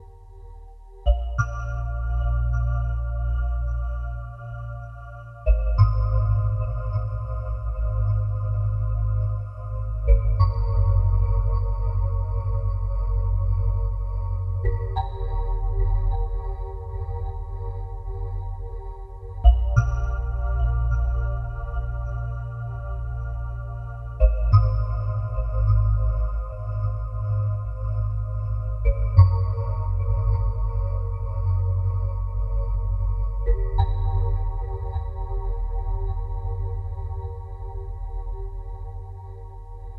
I am loving the sound of this synth, it sounds soo full whatever type of sound you conjure up. No effects mind you except some delay :wink: